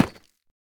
Minecraft Version Minecraft Version 1.21.5 Latest Release | Latest Snapshot 1.21.5 / assets / minecraft / sounds / block / deepslate_bricks / place1.ogg Compare With Compare With Latest Release | Latest Snapshot